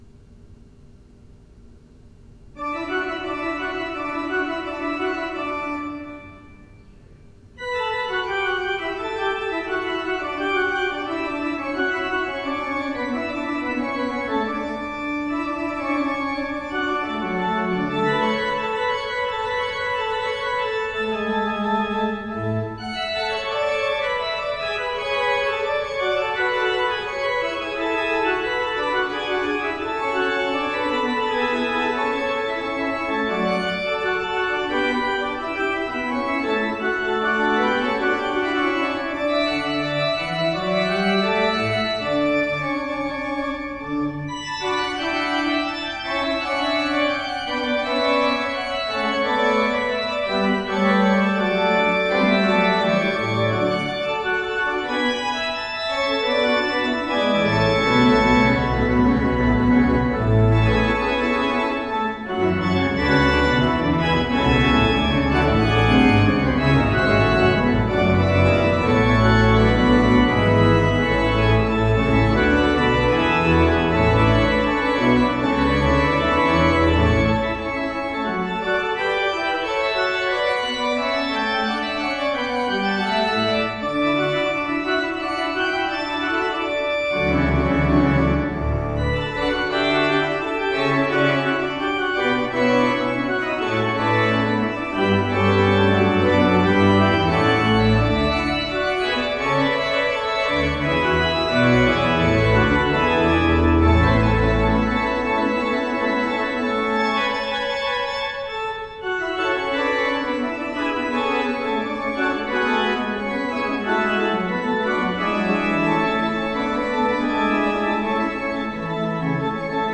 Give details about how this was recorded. Postludes played at St George's East Ivanhoe 2024 The performances are as recorded on the Thursday evening prior the service in question and are recorded direct to PC using a Yeti Nano USB microphone..